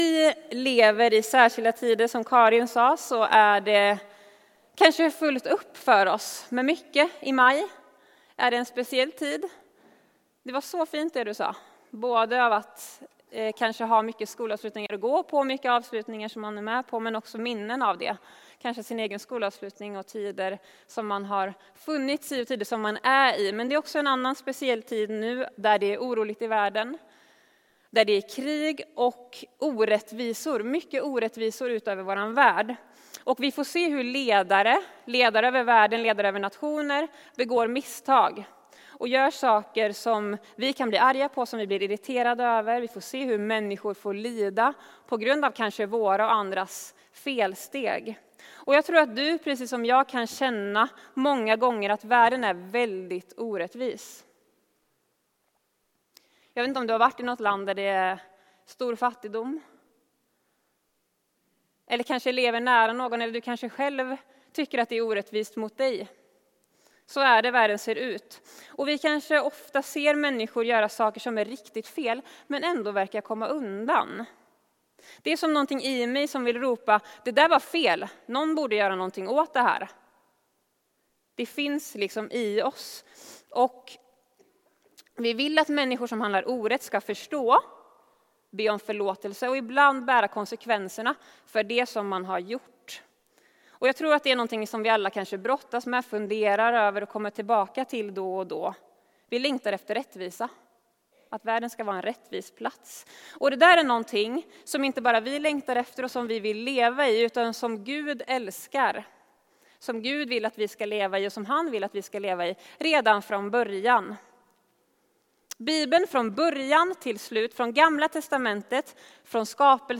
Undervisning från Trollhättan Pingst.